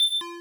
Robot Beep
alert application beep bleep blip bloop bootup bot sound effect free sound royalty free Sound Effects